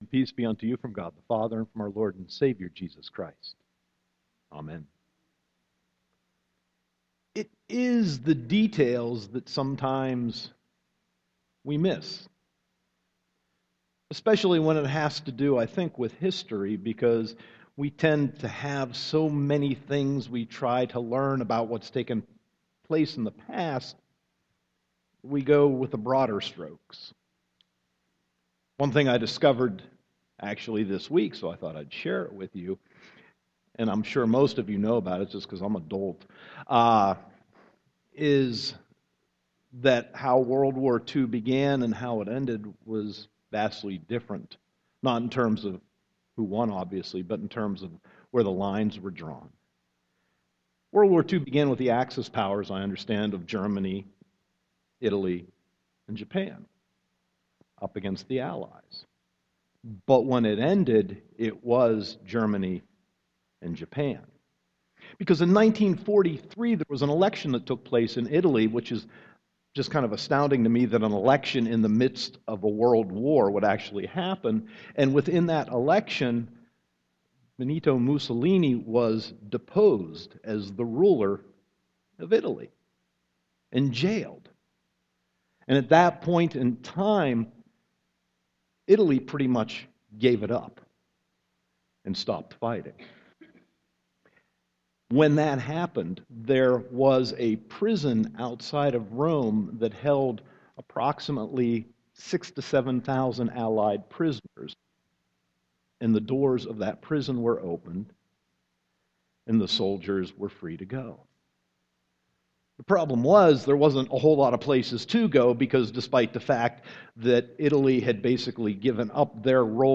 Sermon 9.14.2014 -